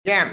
buck martinez yeah Meme Sound Effect